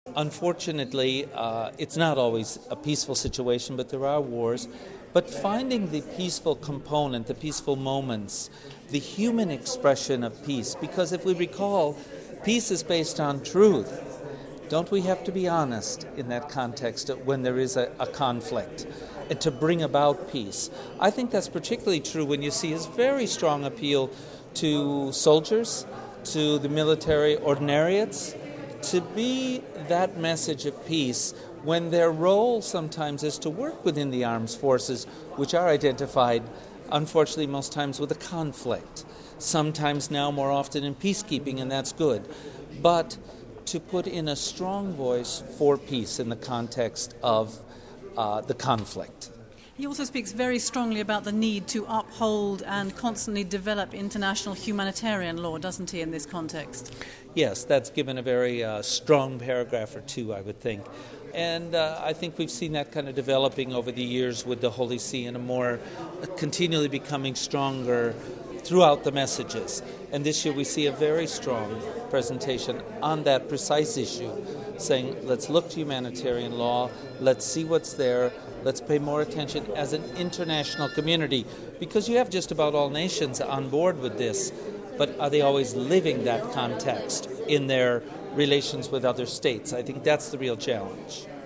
We spoke to Monsignor Frank Dewane of the Pontifical Council for Justice and Peace who launched the document.